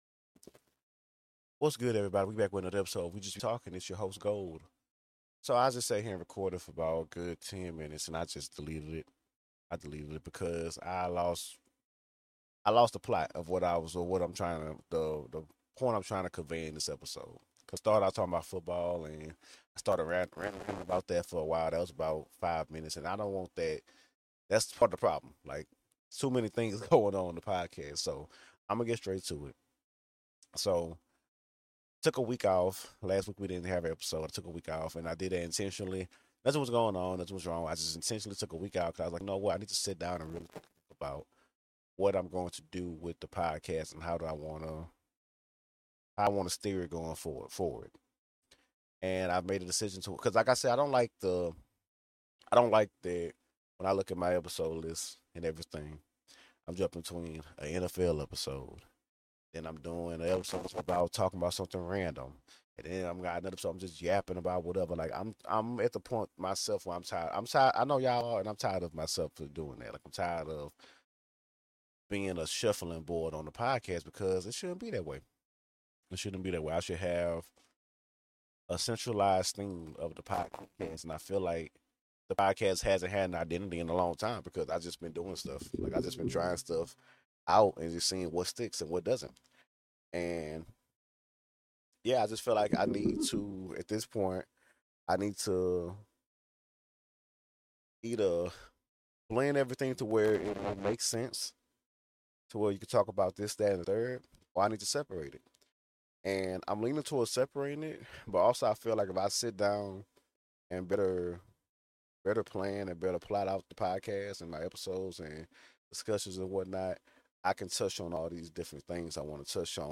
Quick Update (Sorry About Audio!)